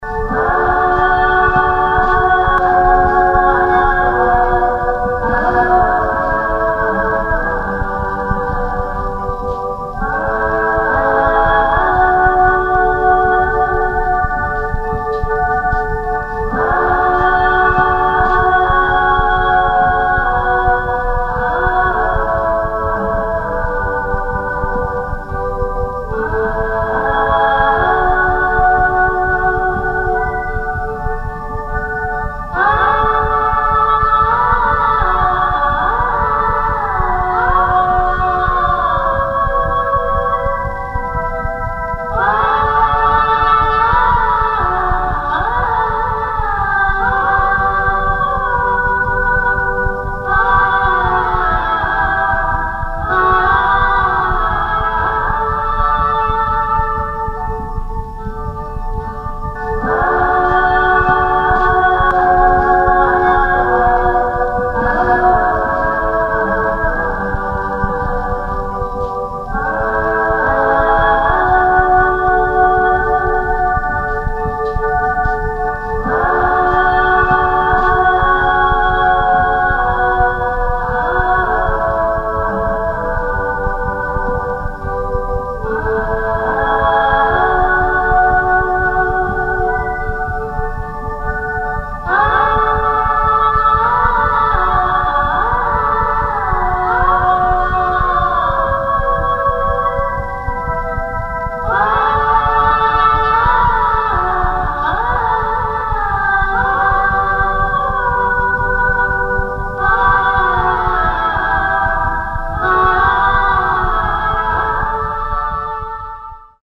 title music in choir